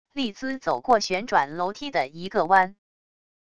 丽兹走过旋转楼梯的一个弯wav音频